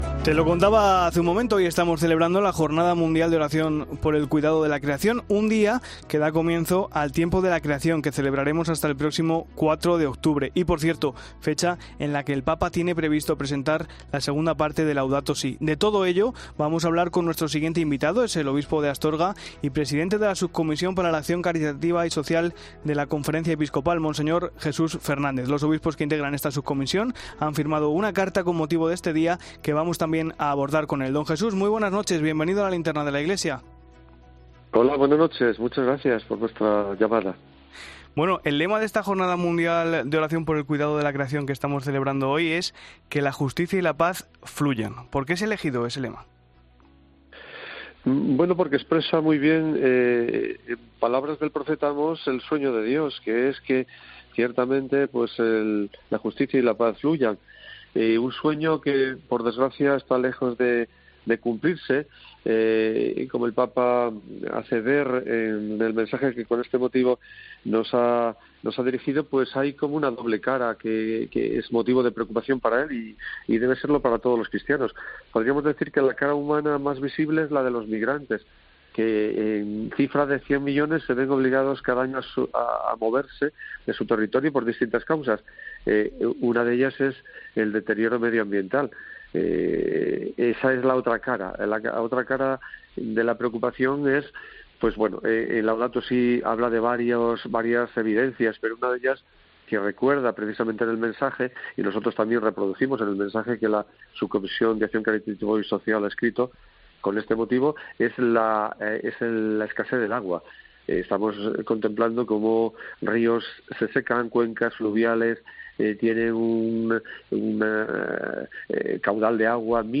El obispo de Astorga y presidente de la Subcomisión para la Acción Caritativa y Social de la Conferencia Episcopal Española ha estado en 'La Linterna de la Iglesia'